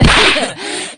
p4u-shadowaigis-laugh2.opus